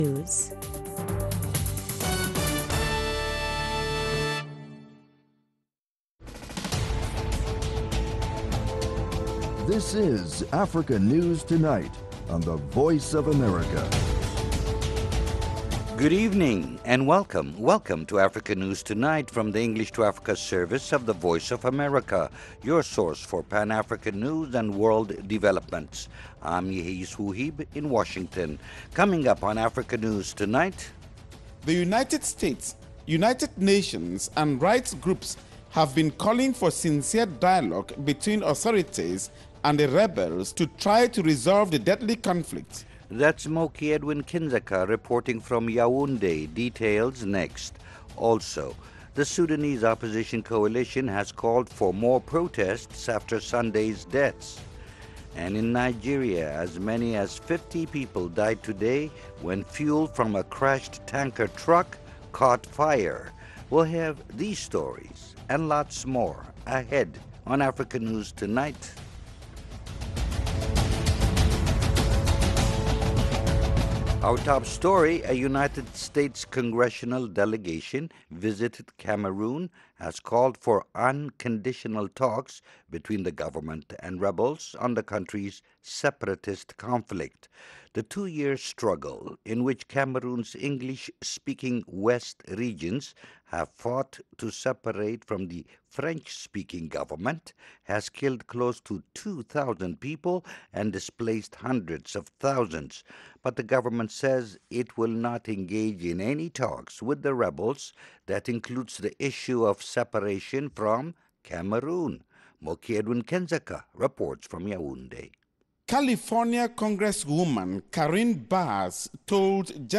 Africa News Tonight is a 30-minute news magazine program that airs twice each evening Monday through Friday, at 1600 and 1800 UTC/GMT.